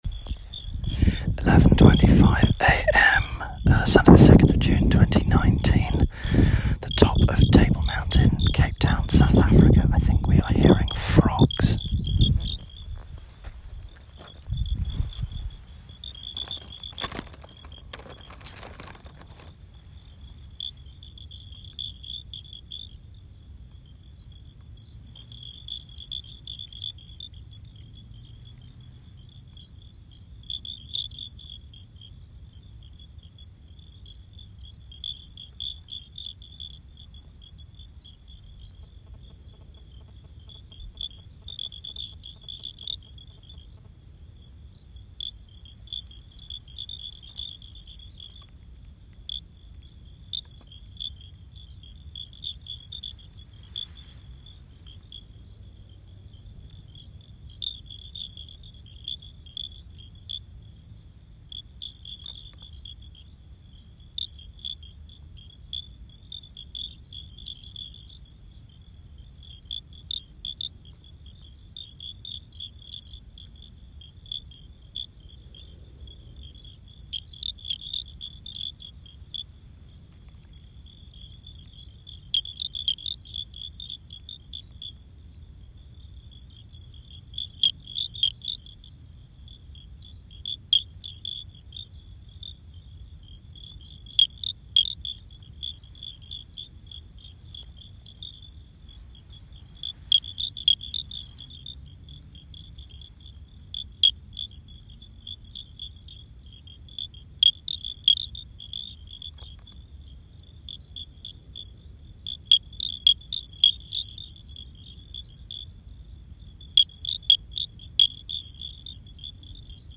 Frogs on Table Mountain
Frogs recorded at the top of Table Mountain, Cape Town